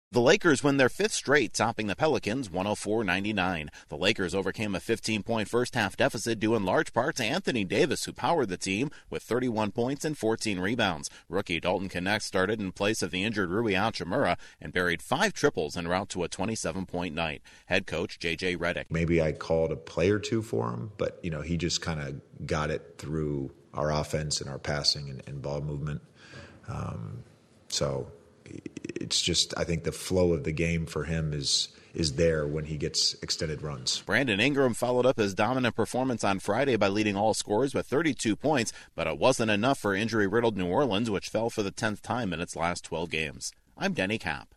The Lakers outlast the Pelicans in a battle of beat up teams. Correspondent